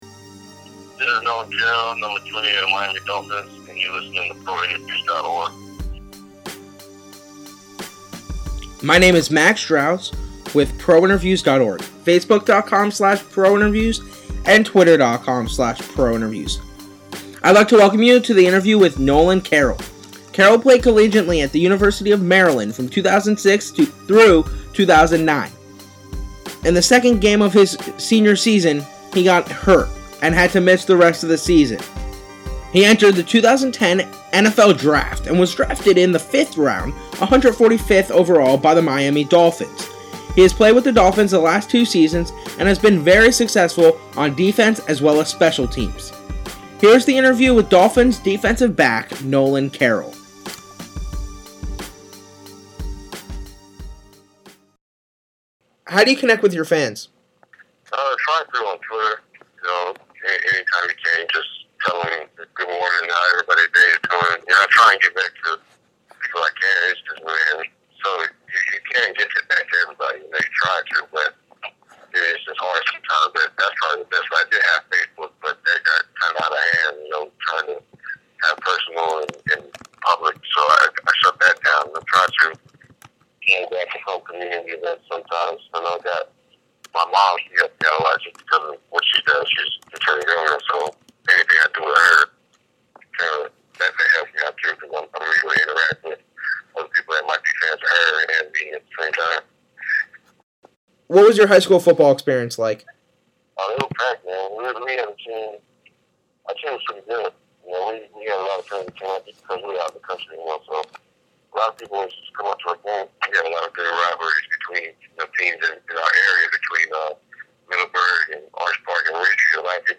Dolphins DB, Nolan Carroll Interview
This interview was done after the 2011 NFL season.
interview-with-nolan-carroll.mp3